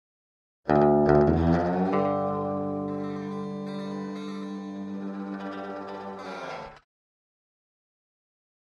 Electric Guitar Twang - Texas Melody 4, (Slide Guitar) - Clean Guitar